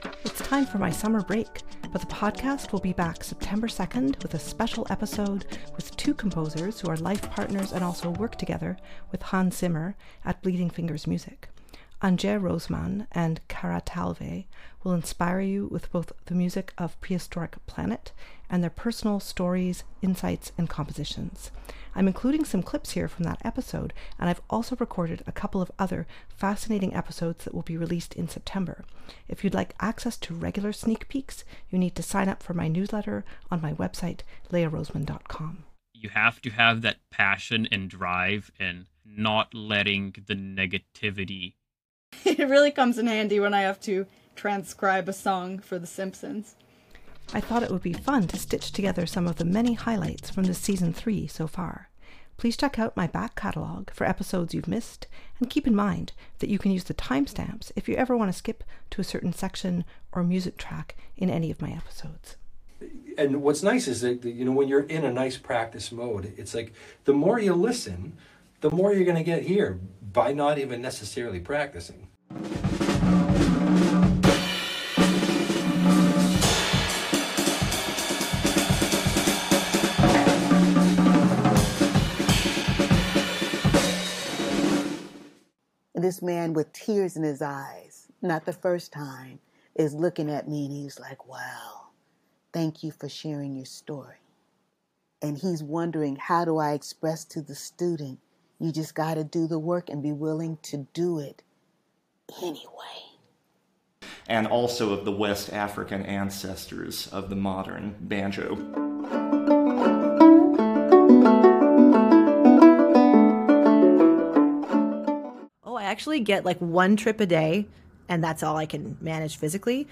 I had a lot of fun stitching together some of the many highlights from this Season 3 so far, in a 10 minute compilation I’ve posted to both my podcast and here on my YouTube: I've included clips from every full episode in 2023 so far in this video!